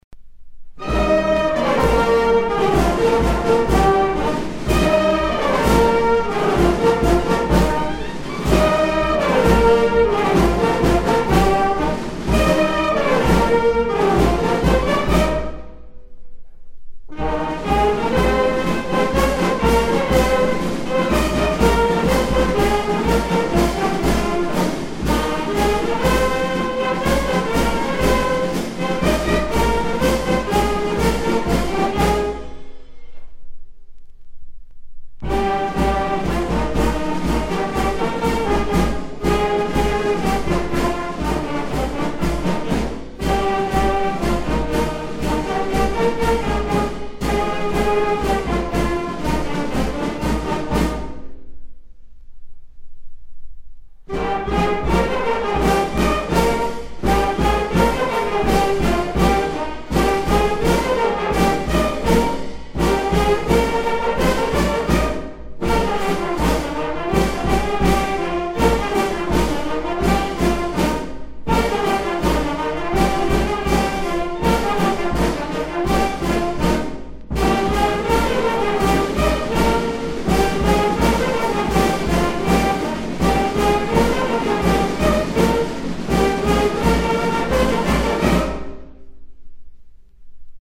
Airs pour les tournois de joutes givordines joués par la fanfare de joutes de Chavanay